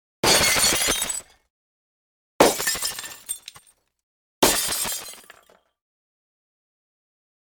VASOS ROTOSROTOS
Ambient sound effects
Vasos_rotosrotos.mp3